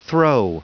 Prononciation du mot throe en anglais (fichier audio)
Prononciation du mot : throe